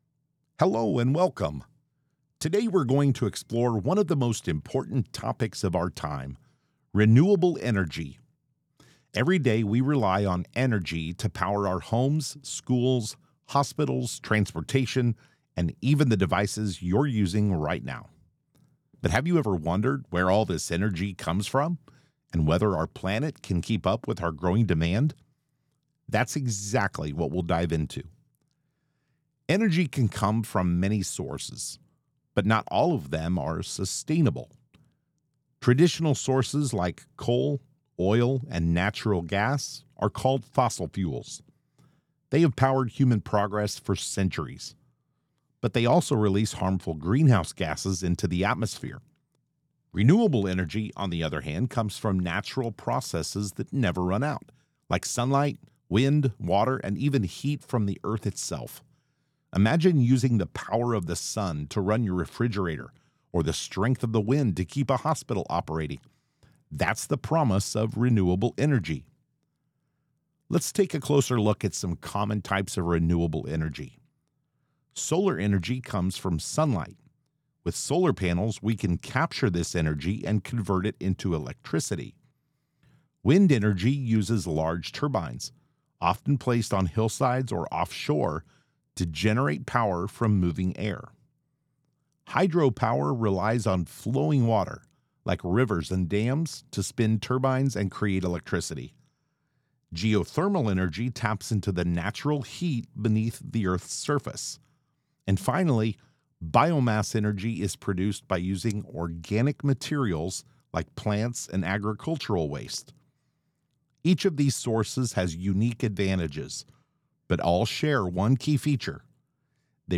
Narration - "Renewable Energy Video" (2 min)